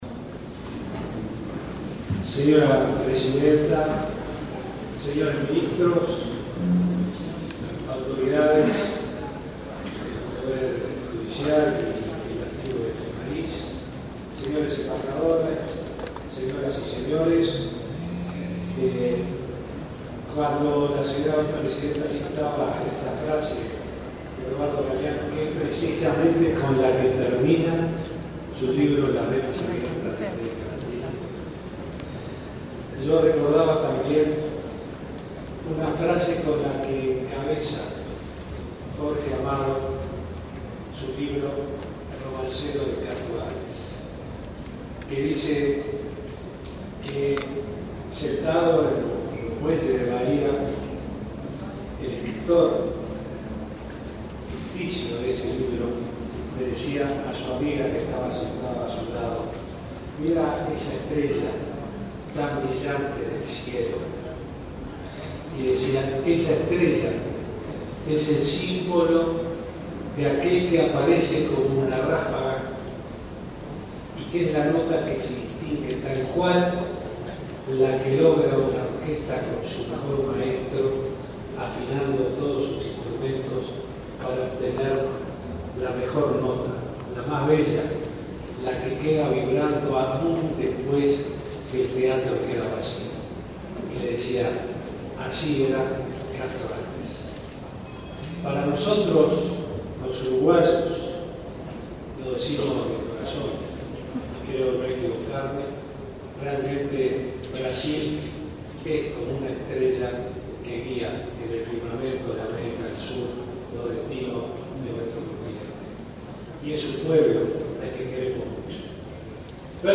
Discurso de Vázquez durante almuerzo